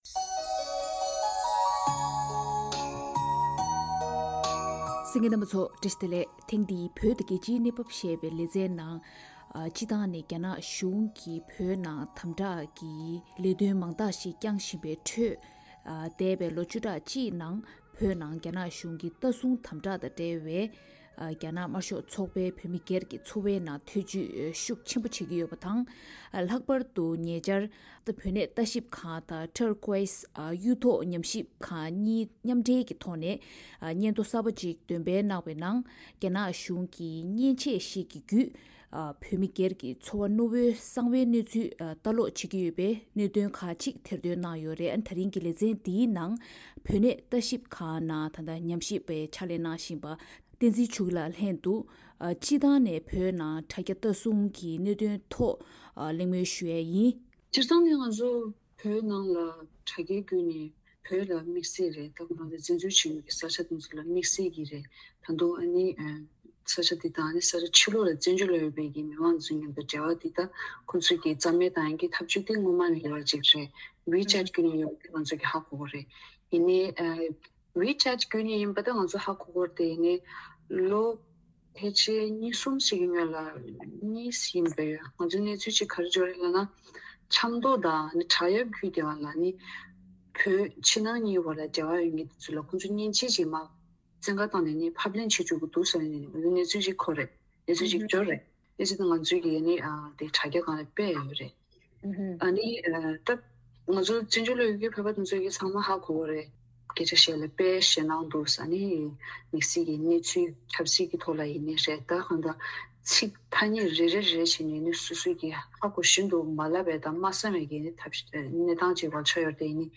བོད་ནང་བོད་མིའི་བདེ་འཇགས་དང་གསང་རྒྱའི་རང་དབང་ལ་དོ་ཕོག་གཏོང་བཞིན་པའི་གནད་དོན་ཐོག་གླེང་མོལ་ཞུས་པ།